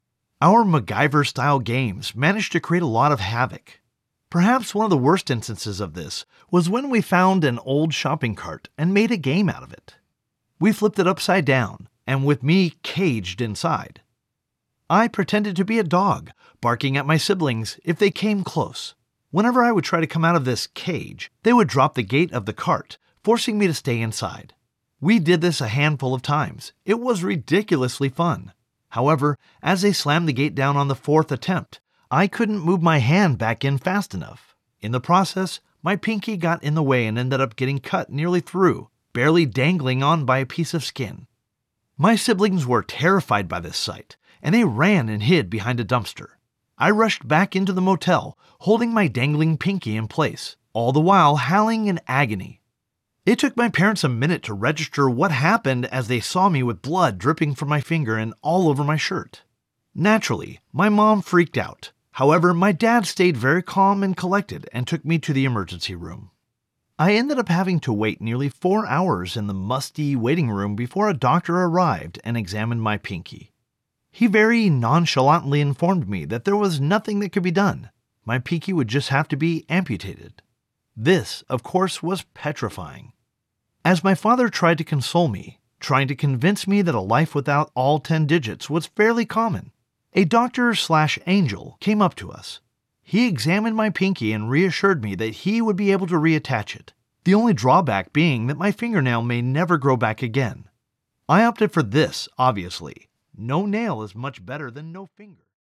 A versatile voice actor providing broadcast-quality narration and commercial voiceover from my professional home studio.
Audiobook Example
English - Western U.S. English
Middle Aged
I record from my home studio equipped using professional equipment to ensure clean, consistent, broadcast-quality audio on every project.